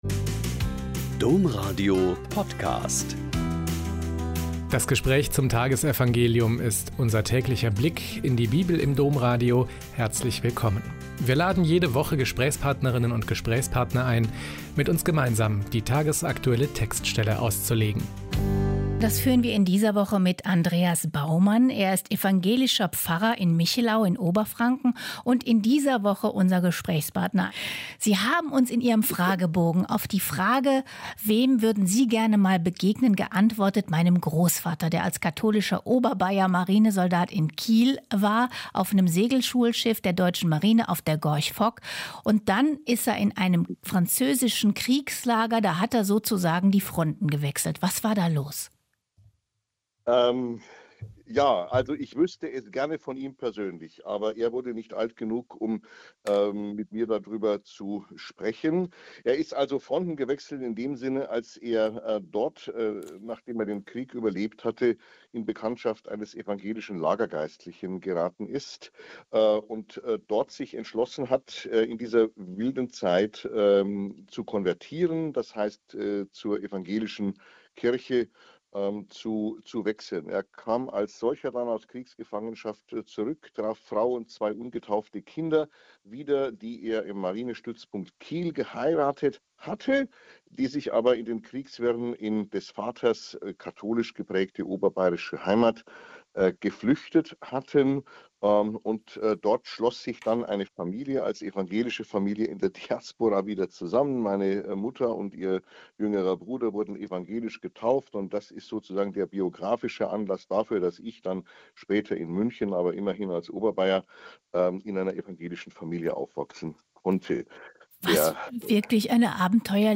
Lk 11,14-26 - Gespräch